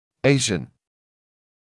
[‘eɪʃn], [eɪʒn] [‘эйшн] азиатский